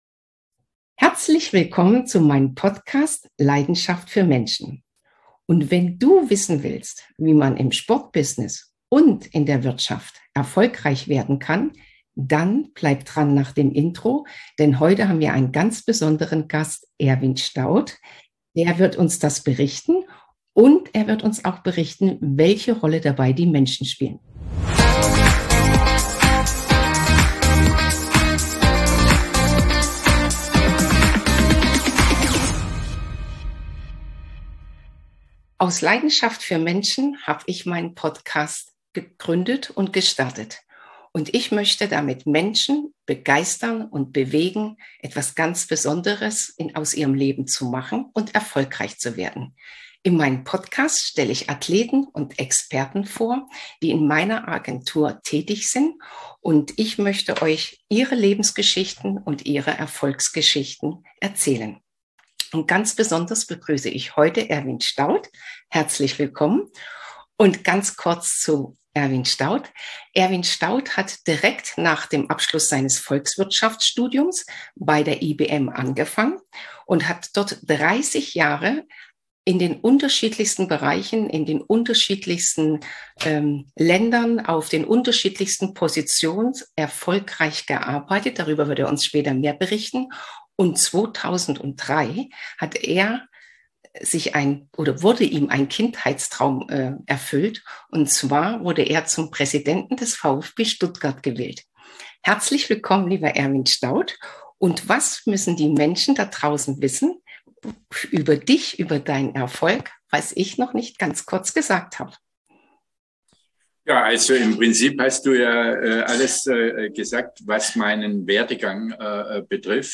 #3 - Ehem. Vorsitzender GF IBM Deutschland und Fußballpräsident Erwin Staudt ... im Interview!